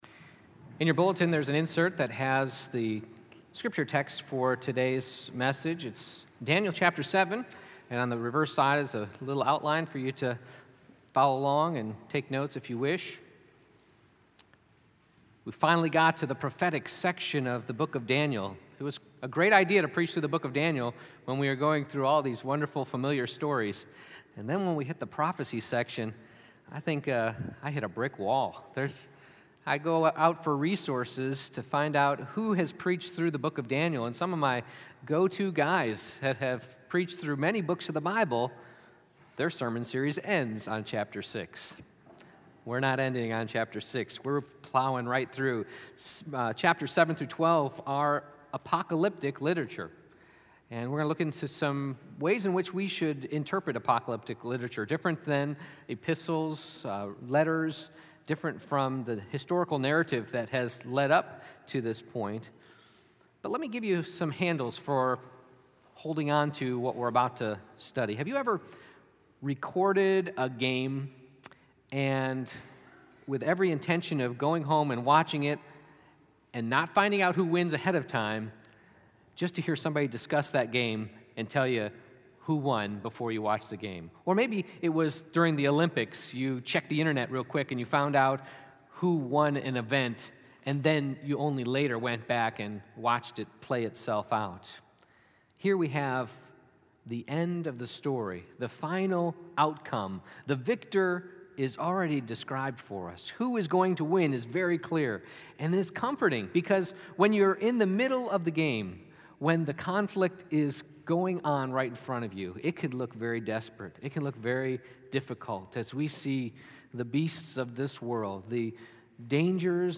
Exposition of Daniel Passage: Daniel 7:1-28 Service Type: Morning Worship « Jesus did What?